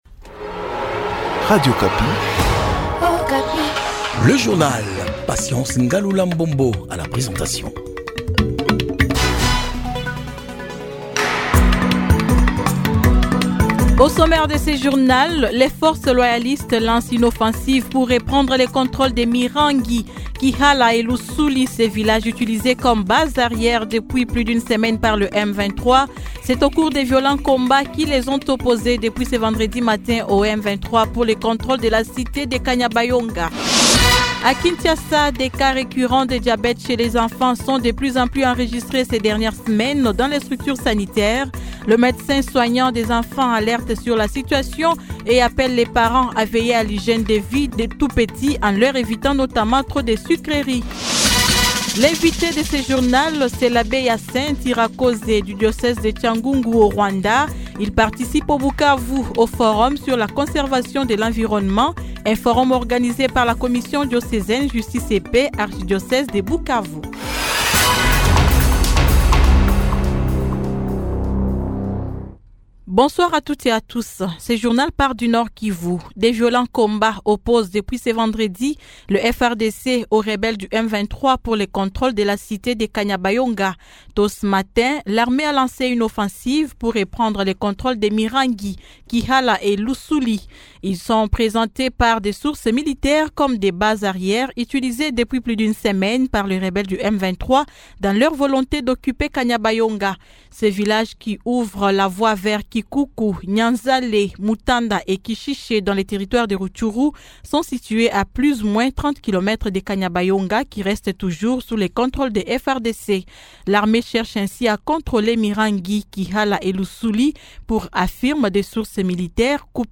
Journal Soir 18H